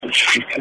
EVP's